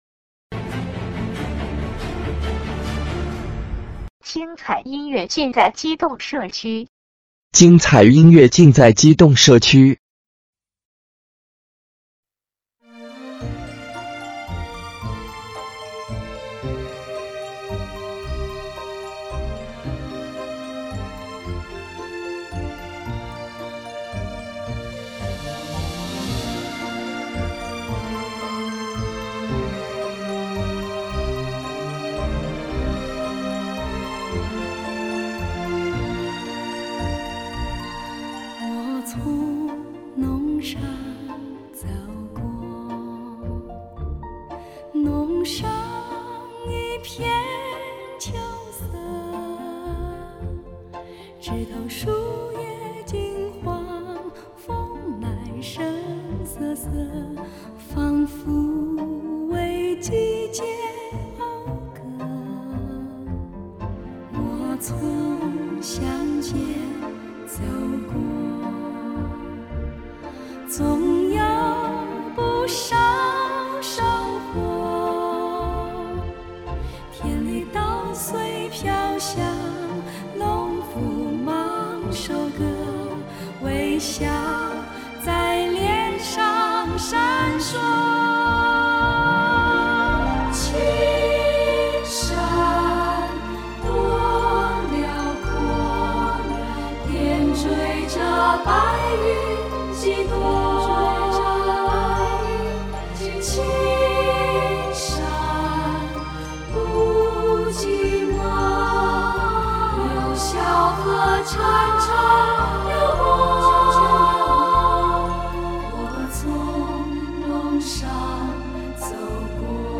怀旧的校园民谣 在歌声中回想自己青葱岁月的校园美好憧憬。